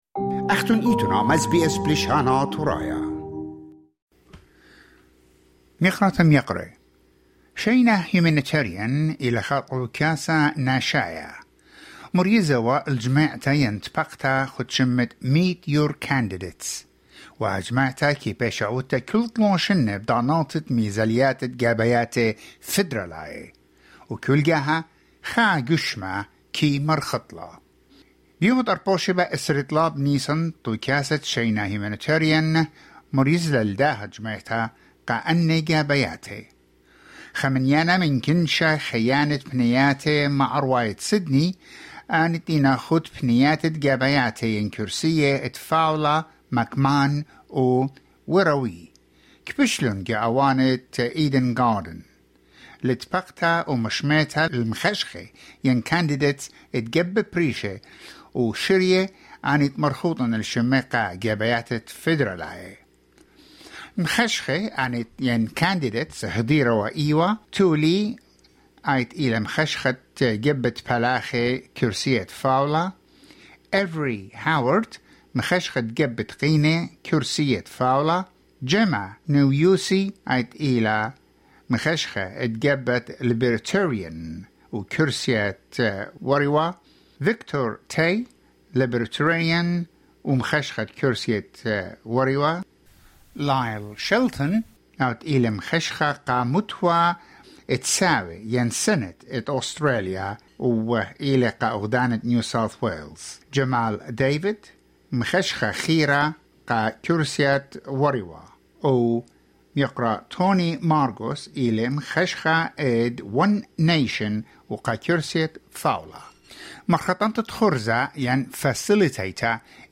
On 23 April, 2023, Shayna Humanitarian hosted a Meet the Candidates forum, bringing together seven candidates from Family First, Labor, The Greens, One Nation, independent and libertarian parties.